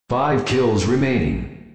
FiveKillsRemaining.wav